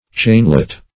chainlet - definition of chainlet - synonyms, pronunciation, spelling from Free Dictionary
chainlet - definition of chainlet - synonyms, pronunciation, spelling from Free Dictionary Search Result for " chainlet" : The Collaborative International Dictionary of English v.0.48: Chainlet \Chain"let\, n. A small chain.